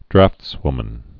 (drăftswmən)